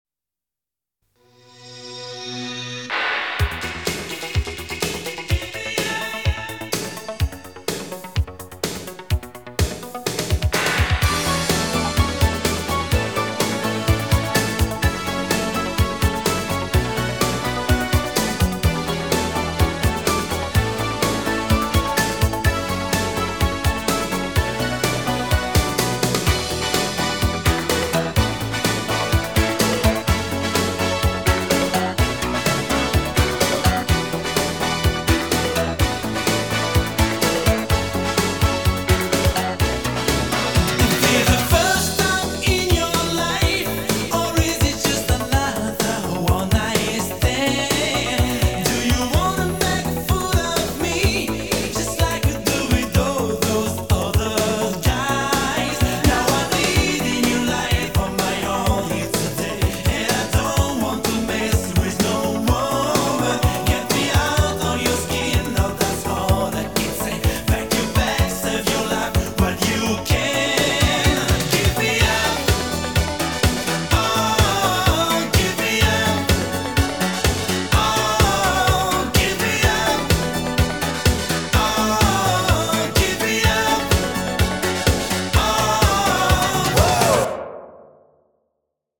BPM126
MP3 QualityMusic Cut